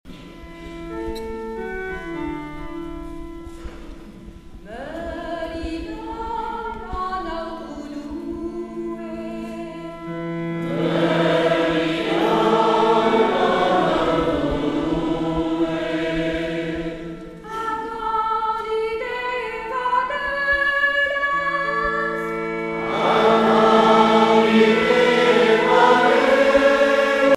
prière, cantique